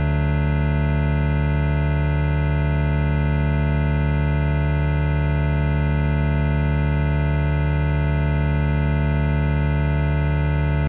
d-chord.ogg